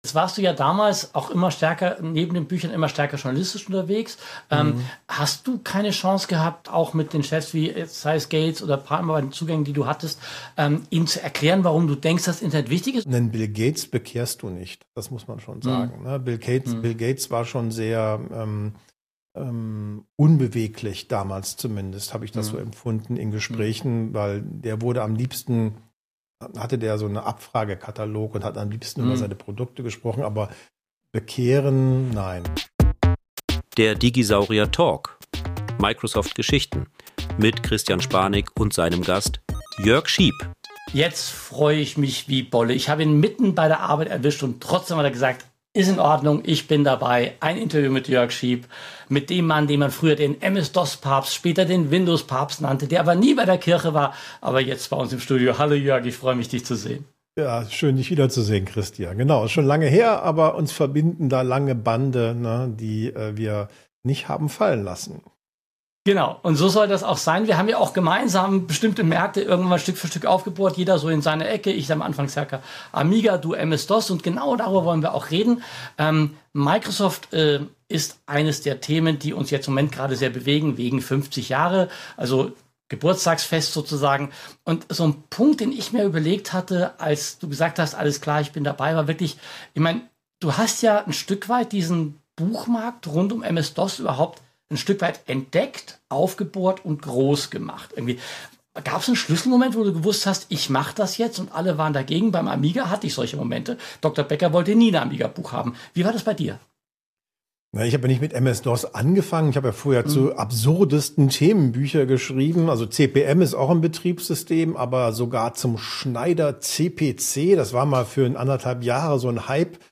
Zum Schluss richtet das Tech-Duo den Blick auf aktuelle Entwicklungen: Wie steht es um Europas digitale Souveränität im KI-Zeitalter? Ein Gespräch voller Nostalgie, Einsichten und überraschender Wendungen für alle, die die digitale Revolution miterlebt haben – oder verstehen wollen, wie alles begann.